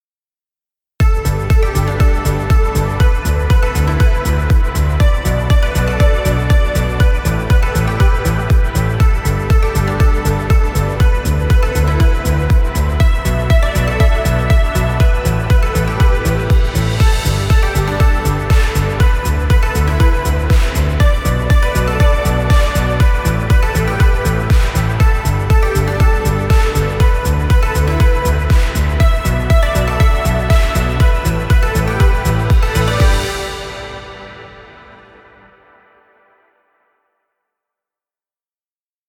positive motivational corporate track.
Upbeat motivational corporate music for video.